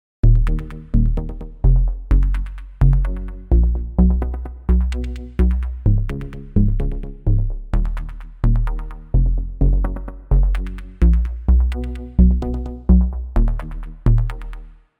光线 深度低音序列 128
Tag: 128 bpm House Loops Bass Synth Loops 4.12 MB wav Key : A